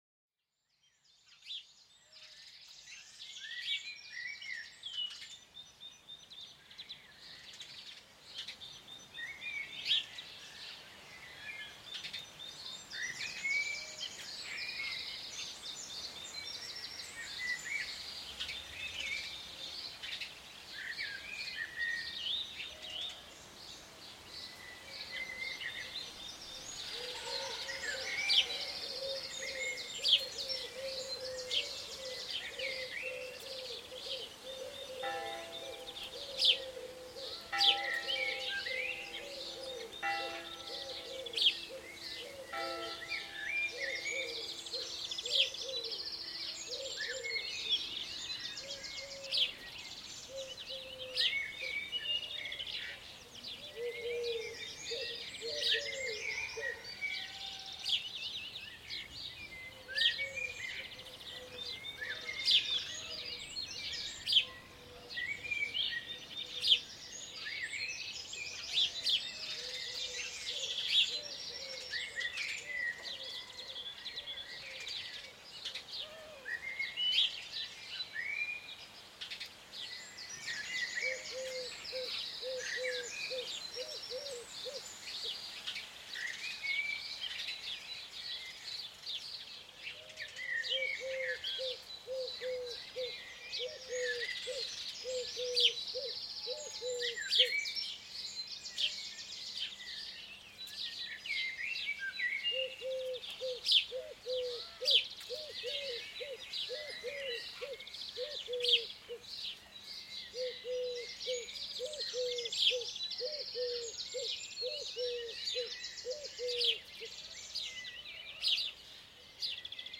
Sumérgete en el paisaje sonoro de un bosque lleno de pájaros al amanecer. Déjate llevar por el canto melodioso y variado que calma la mente y enriquece el alma.